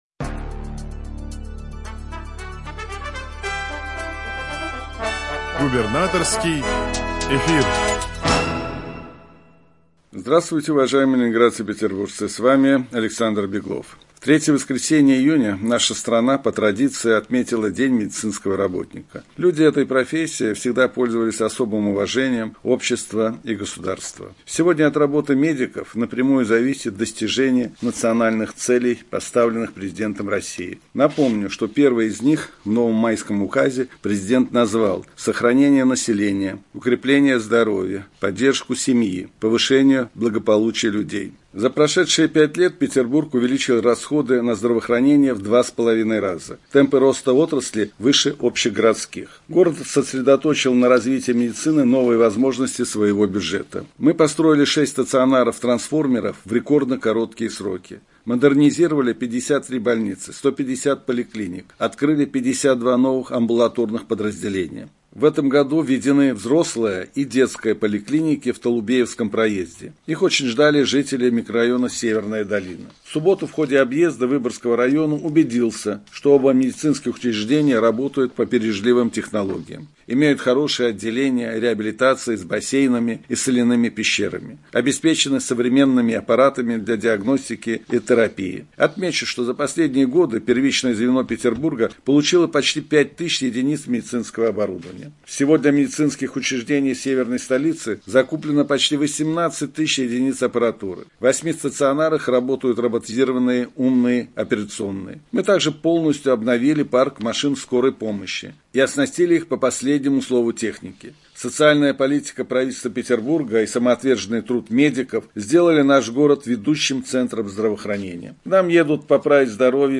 Радиообращение – 17 июня 2024 года